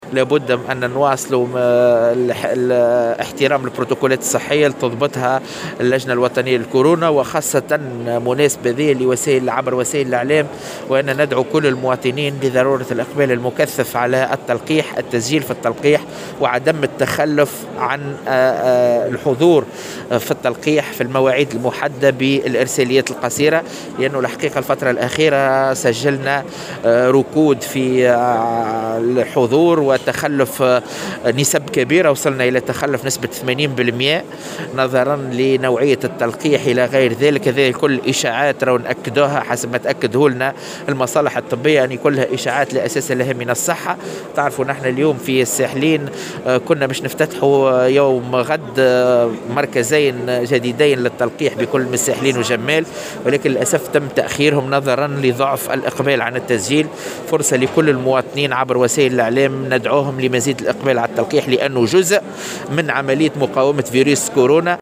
عبّر والي المنستير أكرم السبري، في تصريح للجوهرة أف أم، اليوم الجمعة، عن استيائه من النسبة الكبيرة من المتخلفين عن تلقي التطعيم ضد فيروس كورونا المستجد، والتي بلغت نحو 80 %.
وقال السبري، لدى إشرافه على انطلاق فعاليات معرض الساحل للصناعات التقليدية بقصر المعارض بالساحلين، إن الجهة سجلت في الآونة الأخيرة تراجعا في عدد الحضور للحصول على التطعيم، رغم تلقيهم لإرساليات قصيرة تتضمن موعد تلقيحهم.